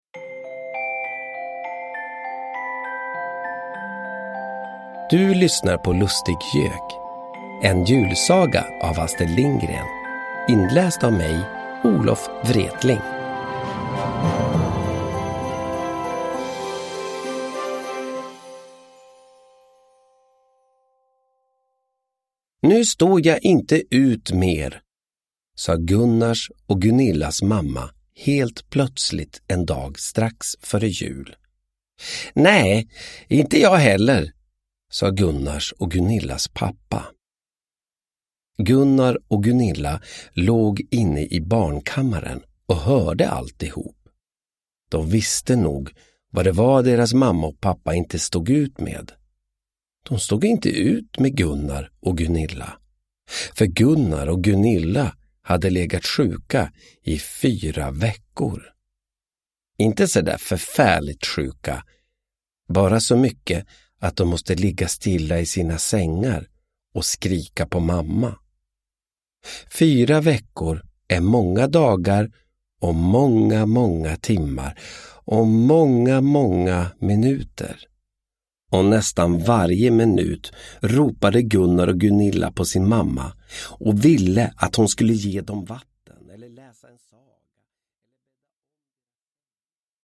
Lustig-Gök – Ljudbok
Vänta på julen och lyssna på Olof Wretling när han läser en mysig julsaga av Astrid Lindgren.
Uppläsare: Olof Wretling